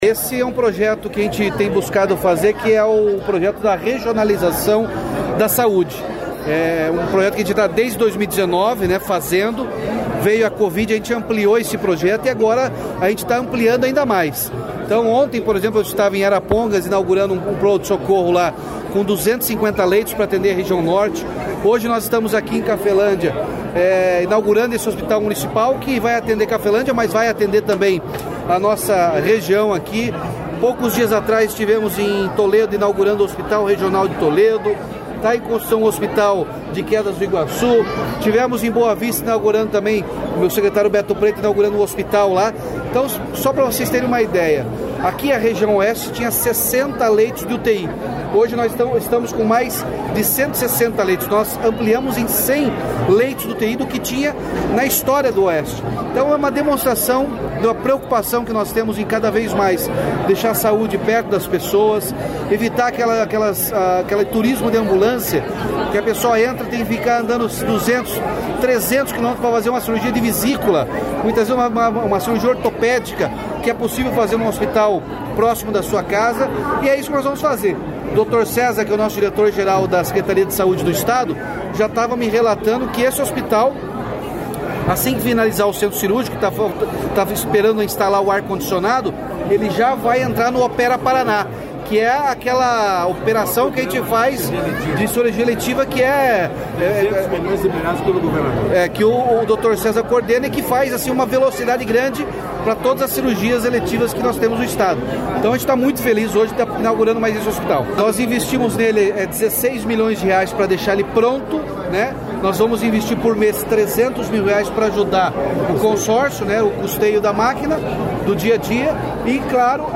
Sonora do governador Ratinho Junior sobre a inauguração do Hospital de Cafelândia